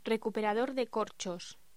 Locución: Recuperador de corchos
voz
Sonidos: Voz humana